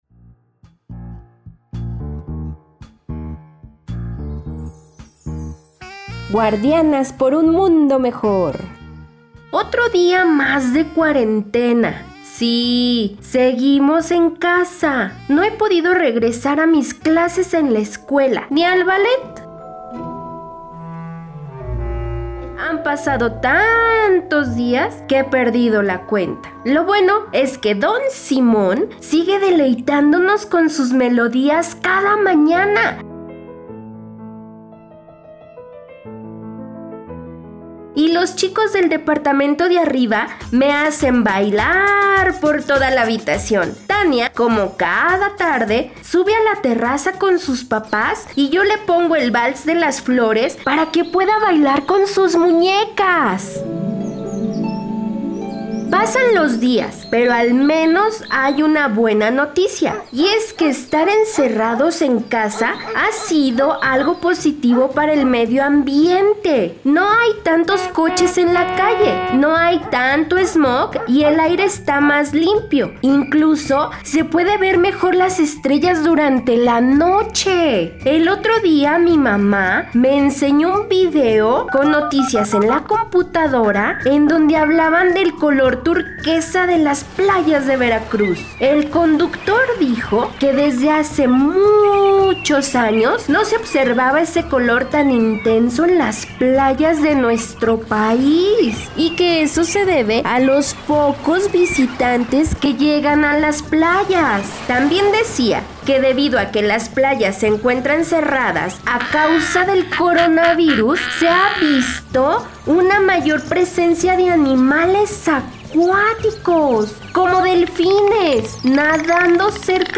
Un audiolibro de AMONITE